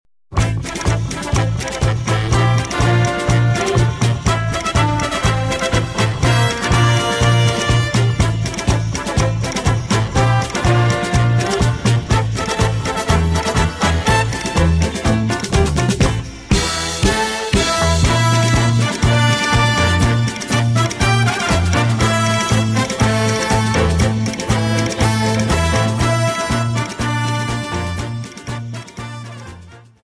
Paso Doble 60 Song